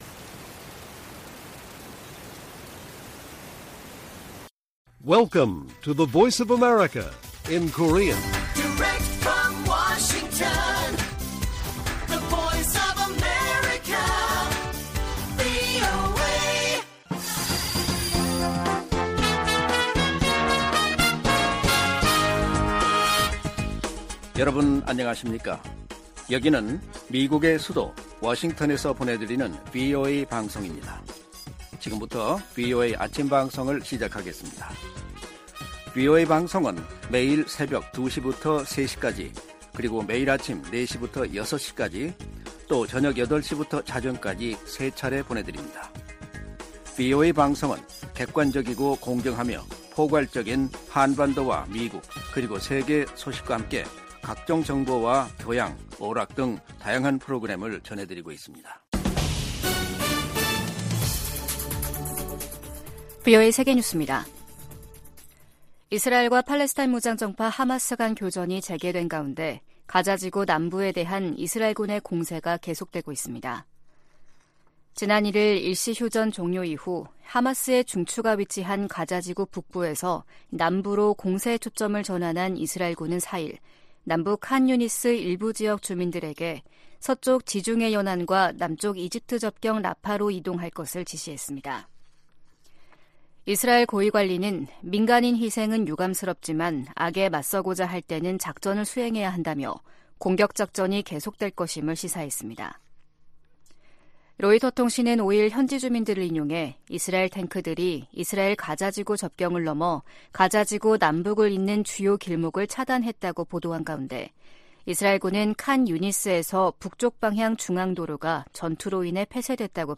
세계 뉴스와 함께 미국의 모든 것을 소개하는 '생방송 여기는 워싱턴입니다', 2023년 12월 6일 아침 방송입니다. '지구촌 오늘'에서는 러시아와 싸우는 우크라이나를 도울 시간과 돈이 바닥나고 있다고 백악관이 경고한 소식 전해드리고, '아메리카 나우'에서는 민주당 주지사들이 조 바이든 대통령에게 낙태권리 등과 정책·입법 성과 홍보를 강화해야 한다고 조언한 이야기 살펴보겠습니다.